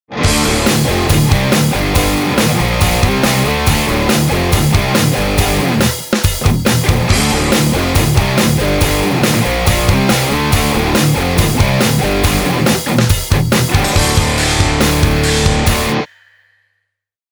Tak som dnes vecer opat dostal testovaciu naladu a vrhol som sa na dalsi test: Celestion Vintage30 - made in China vs. England
Ale podstatne je, ze to hra uplne inak!
Pre kutilov doplnim info o nahravani: na kazdej ukazke su nahrate 3 gitary, v 3 roznych poziciach mikrofonu (chcel som zachytit celu skalu zvuku repraku) a rozhodene v panorame (Left, center, Right)
Na boxoch som si oznacil stredy membran bielou paskou, aby mikrak bol v rovnakej pozicii a este aj vo vzdialenosti od membrany
ta sa mi zdala byť aj krajšia, prvá sa mi zdala mať tyký vácej gulatejší zvuk....